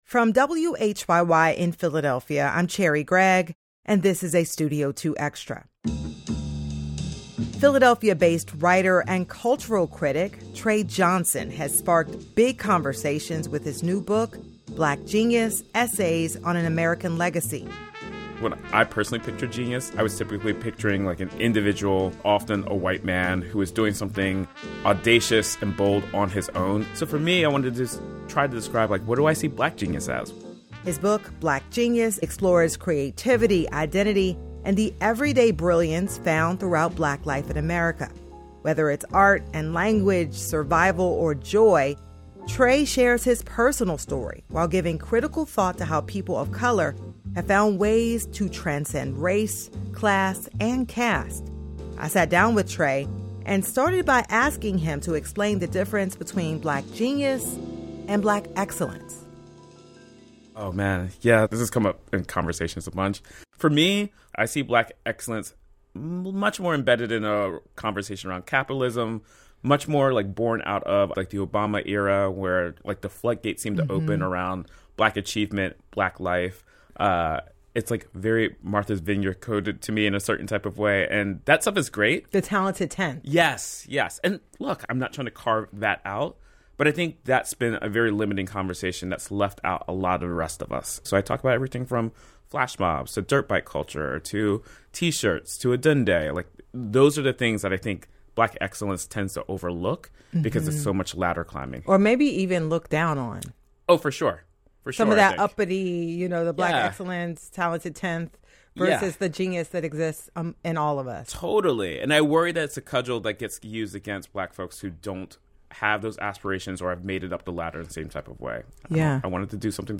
Cellist
Graduation Recital